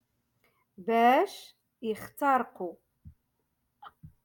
Moroccan Dialect- Rotation Five-Lesson Sixty Four